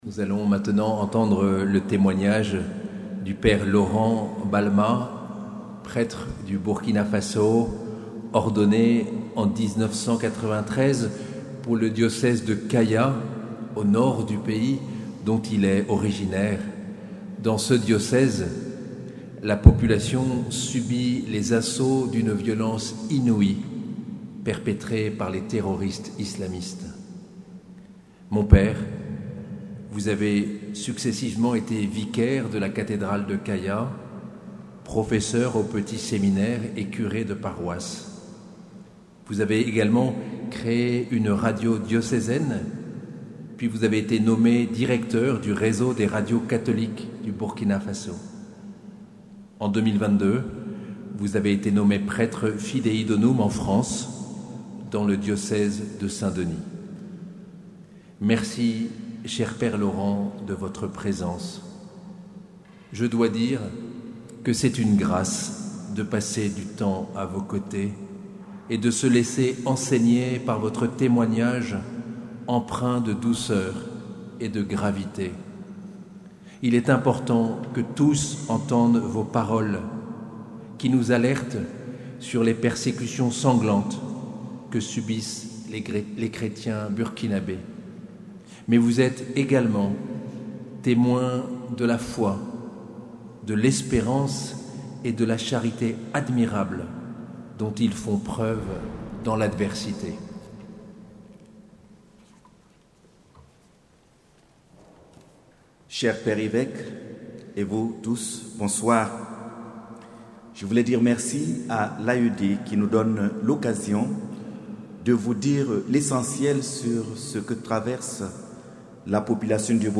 Nuit des Témoins le 21 novembre 2024 - Cathédrale de Bayonne